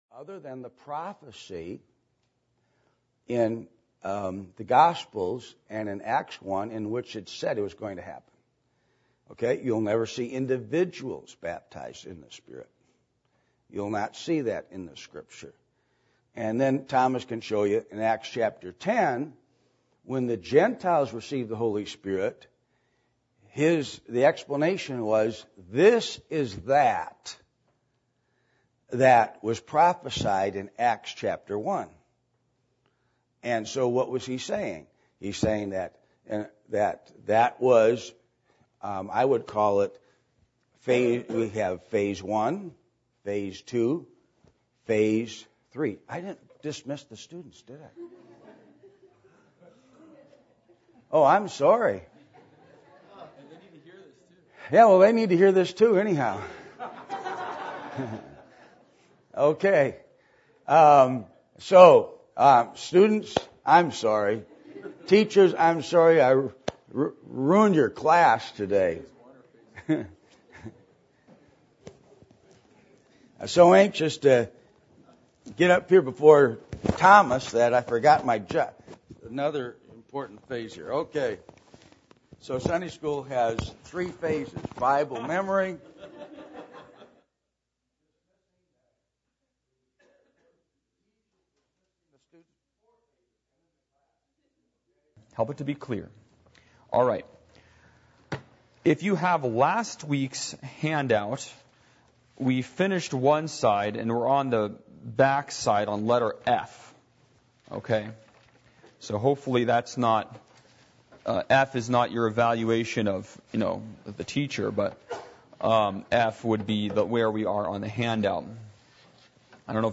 Baptism of the Holy Spirit Service Type: Adult Sunday School %todo_render% « Giving Thanks For Everything The “Satan Free” Zone »